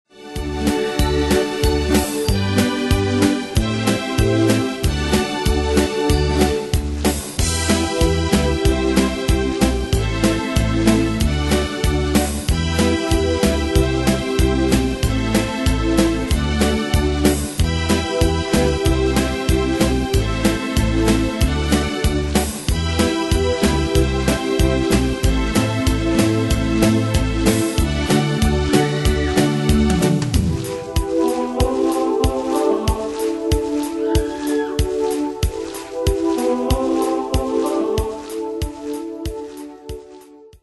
Style: PopAnglo Année/Year: 1984 Tempo: 94 Durée/Time: 4.23
Danse/Dance: Pop Cat Id.
Pro Backing Tracks